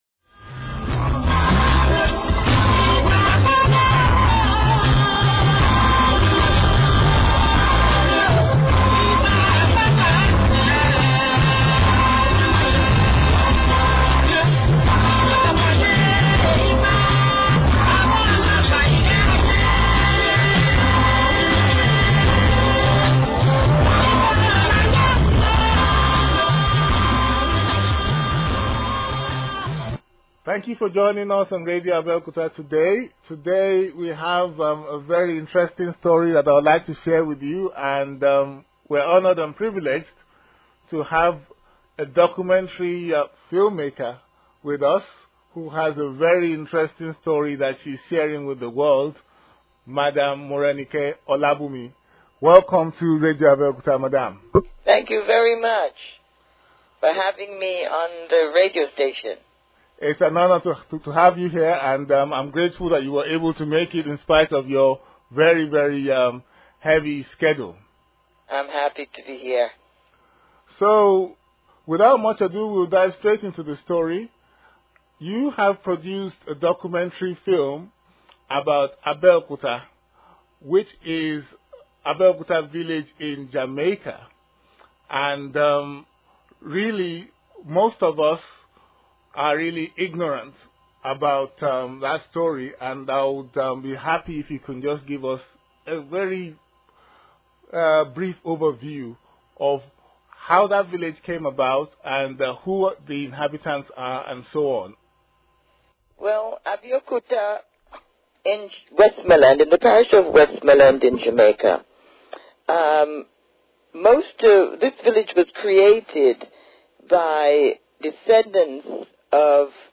Radio Abeokuta's interview